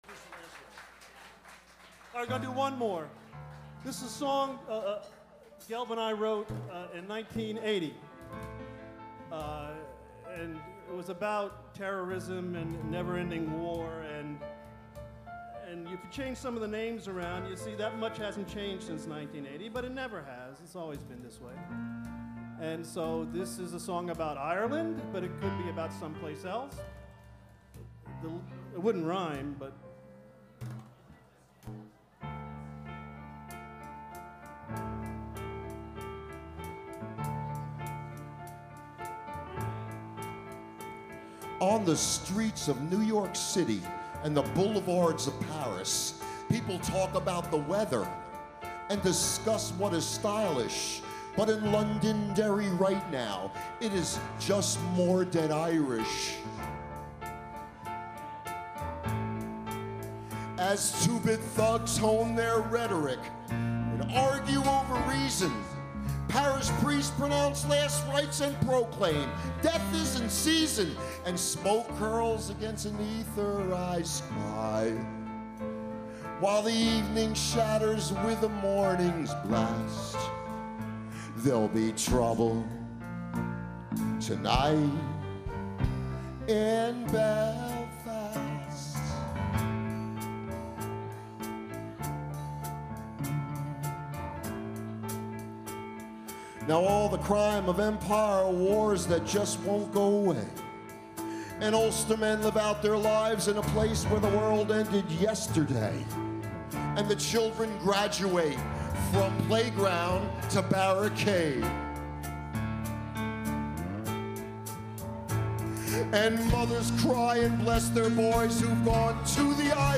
The last gig
cbgb 2006